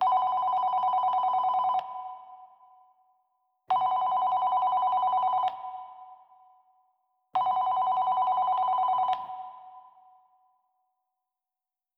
phone_ringing.wav